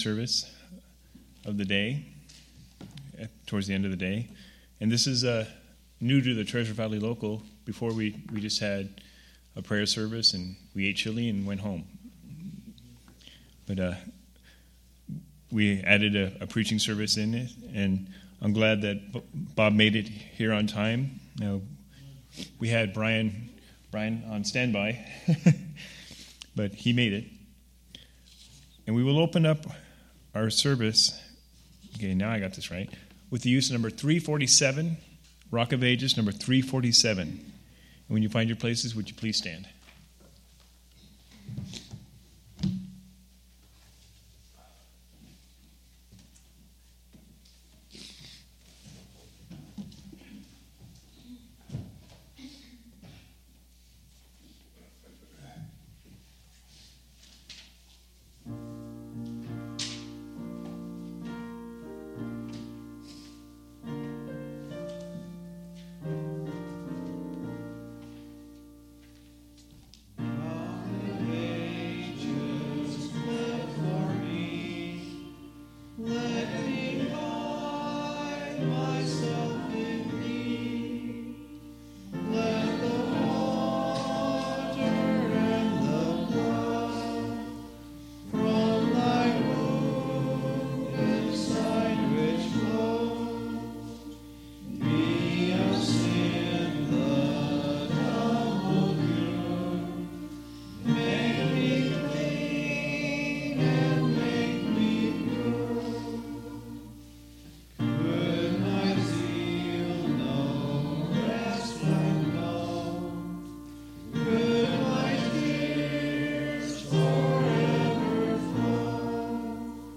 6/3/2016 Location: Idaho Reunion Event